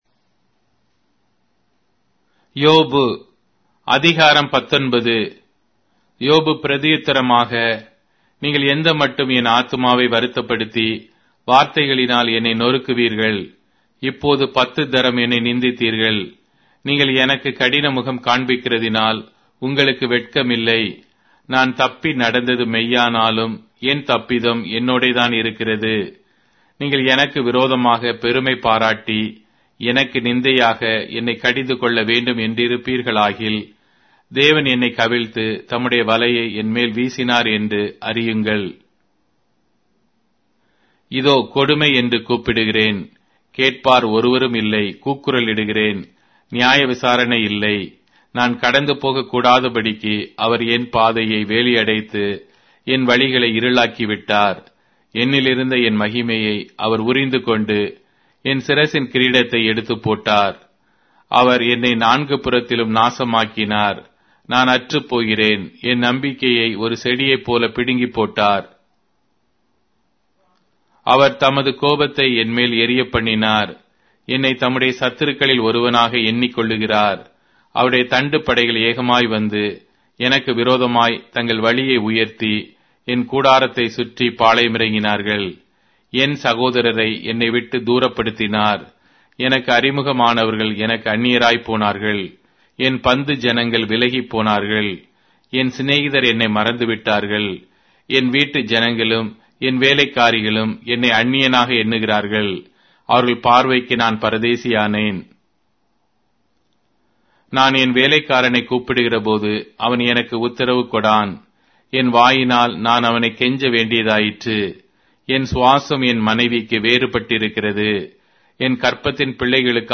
Tamil Audio Bible - Job 36 in Hcsb bible version